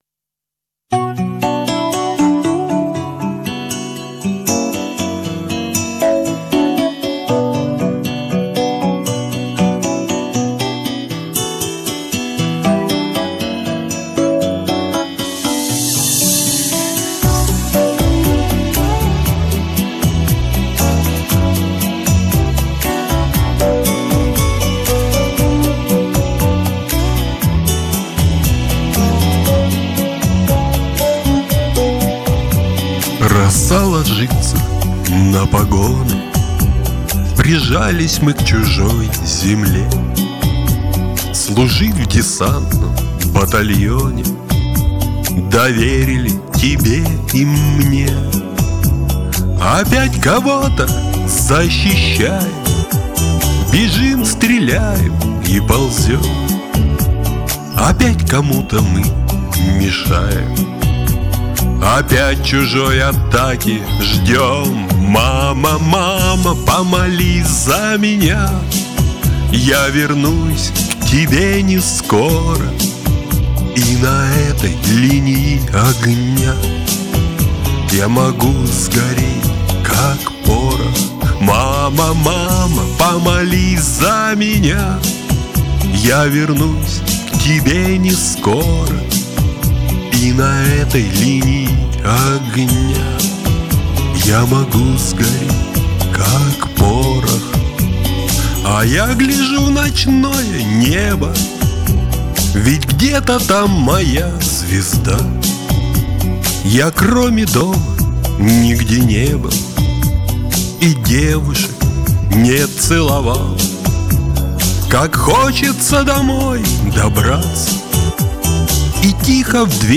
Кавер
Спели хорошо оба, претензий нет.